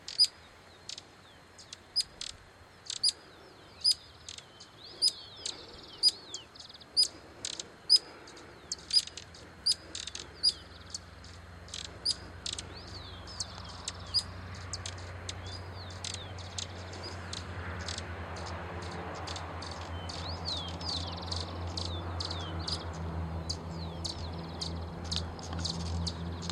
Giant Hummingbird (Patagona gigas)
Condition: Wild
Certainty: Recorded vocal
picaflor-gigante1.mp3